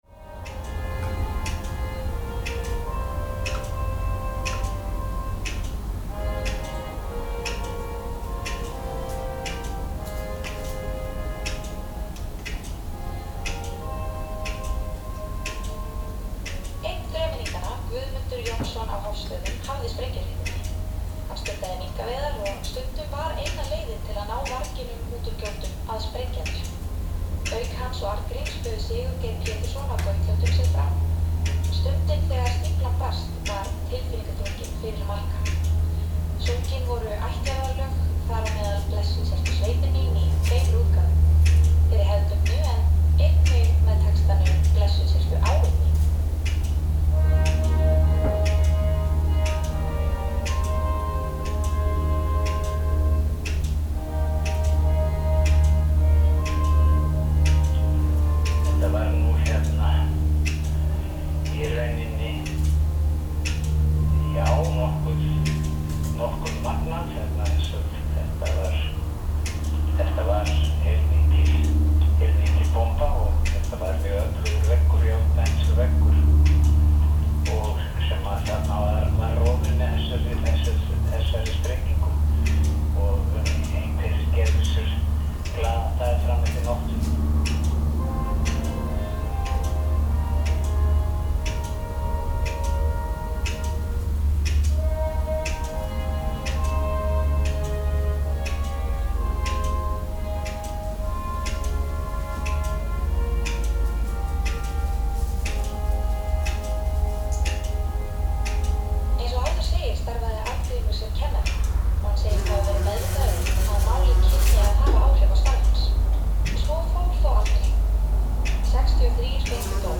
Lewitt LCT540s vs. Rode NT1a in silence
Both have same specification of lowest self noise on the market, they have their own characteristics and after my NT1a mod, they seems to have slightly different polar pattern.
NT1a is extremely well focused in the mid-range 500hz – 5Khz. which is exactly the frequency where most common birds sing.
But sometimes NT1a sounds „flat“ because NT1a is rather poor on low frequency, sometimes like out of phase (which could be as well caused of wrong setup).